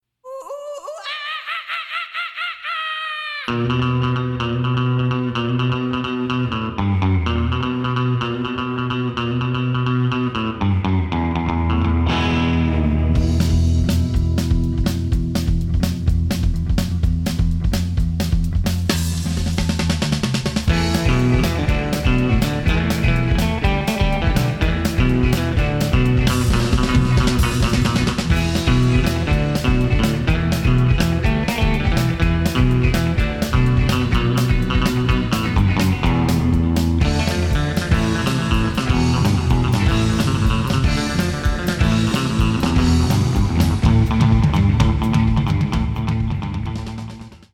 klassischer Spy-Surf mit hoher Energie und toller Melodie.